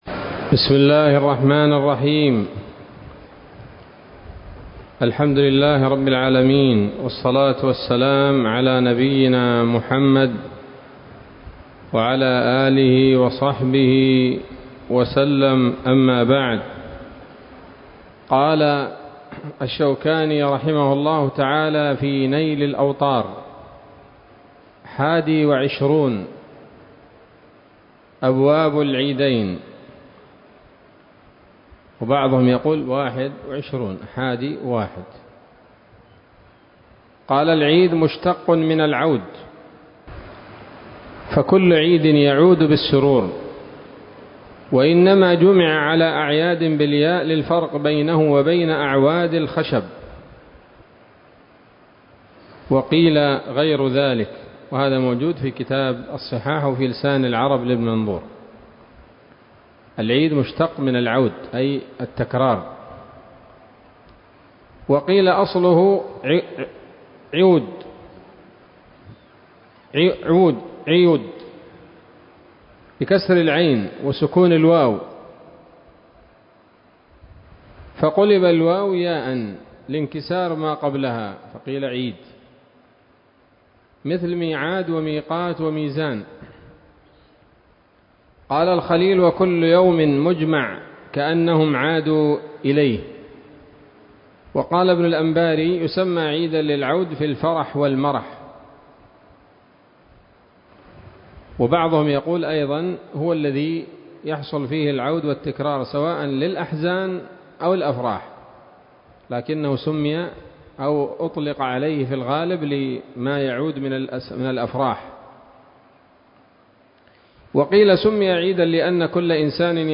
الدرس الأول من ‌‌‌‌كتاب العيدين من نيل الأوطار